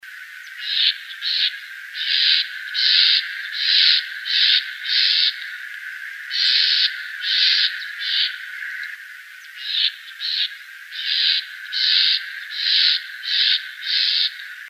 Southern House Wren (Troglodytes musculus)
Location or protected area: Reserva Ecológica Costanera Sur (RECS)
Condition: Wild
Certainty: Photographed, Recorded vocal